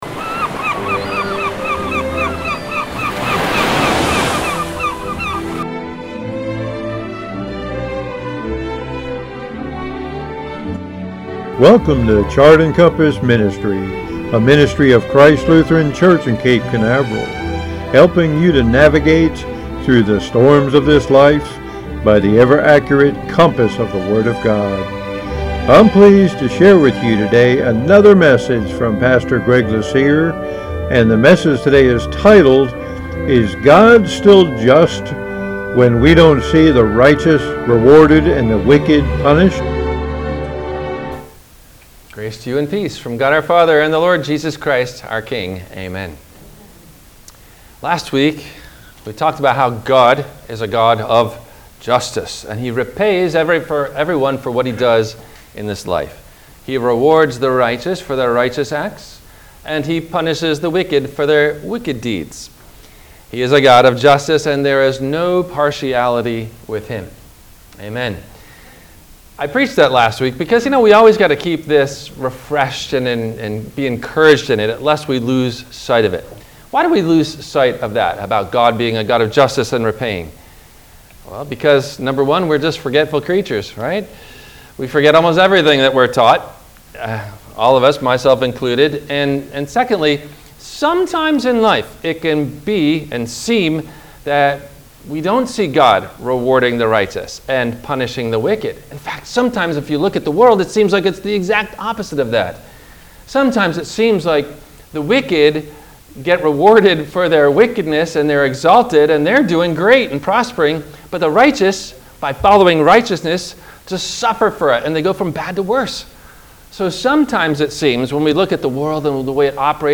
– WMIE Radio Sermon – September 09 2024
No Questions asked before the Radio Message.